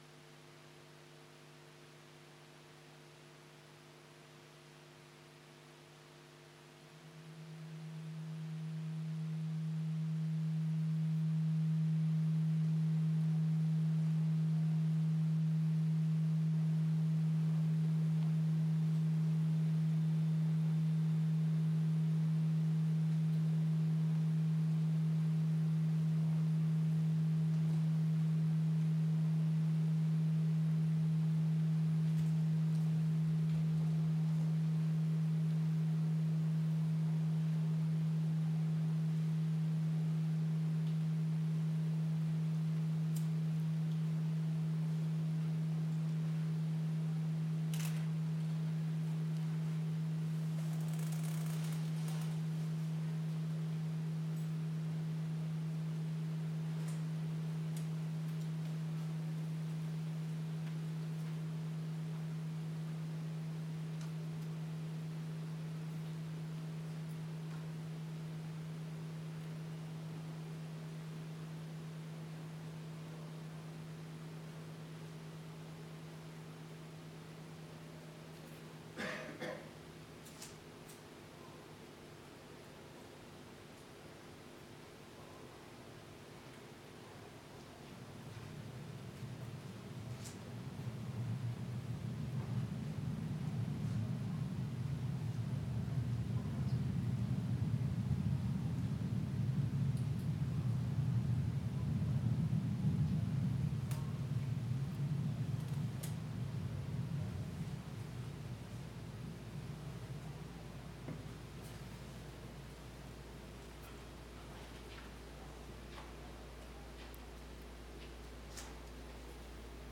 trombone
pure sine waves